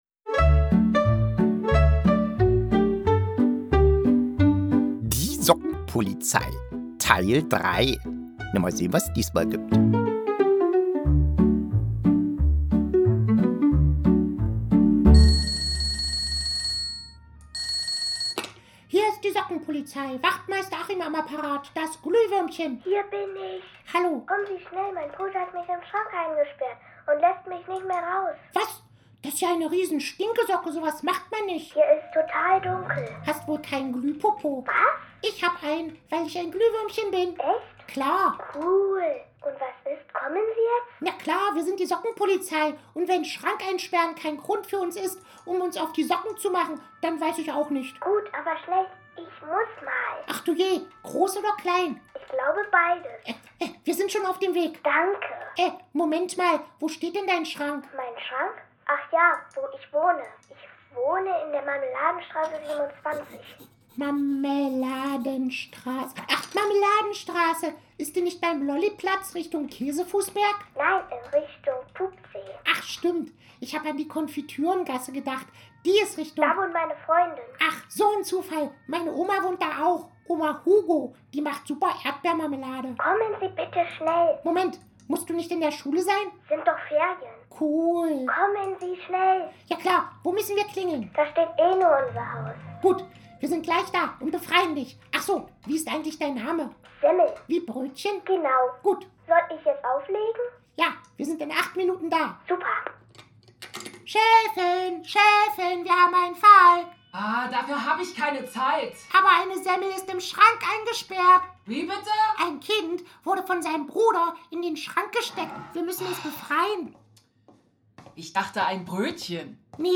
Wie letztes Jahr vor Weihnachten gibt es hier im Podcast wieder ein Hörspiel von mir.
hoerspiel-die-sockenpolizei-teil-3.mp3